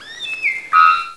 (All of these samples are 8-bit, sorry.)
Three-Wattled Bell-bird
The Bell-bird page presents the discovery that the Bell-bird's distinctive metallic "bonk" sound appears to consist of three simultaneous tones roughly a semitone apart, perhaps created by multiple independent vocal resonators.
bell-bird.wav